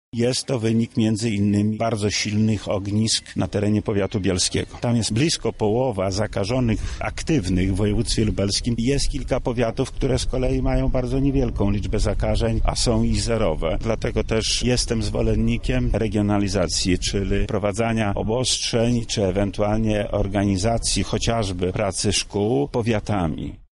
Lech Sprawka